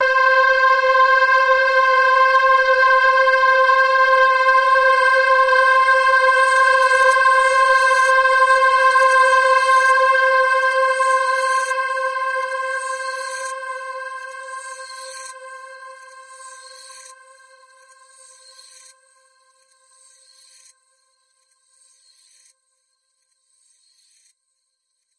描述：这是个非常黑暗和忧郁的多采样合成器垫。演变和空间感。
标签： 环境 黑暗 FLAC 粒状 多重采样 多重采样 合成器
声道立体声